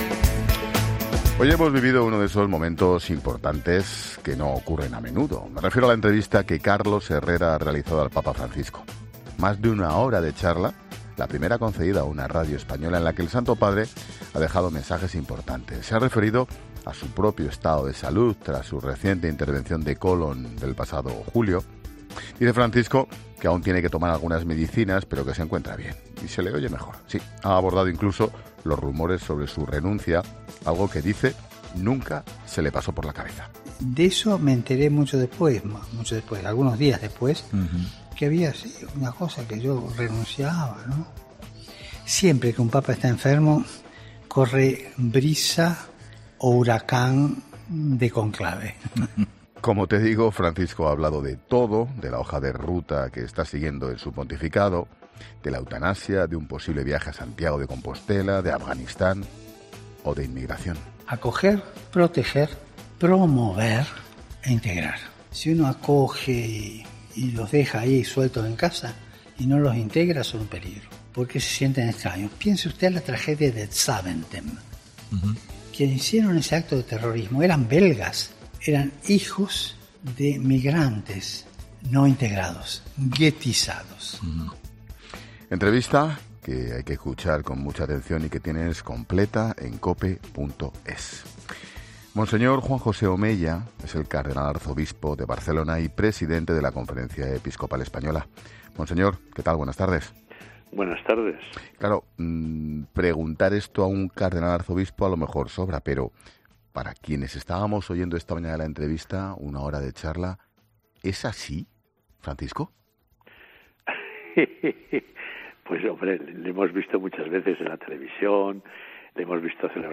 El Presidente de la Conferencia Episcopal Española y Cardenal Arzobispo de Barcelona ha valorado la histórica entrevista del Sumo Pontífice en COPE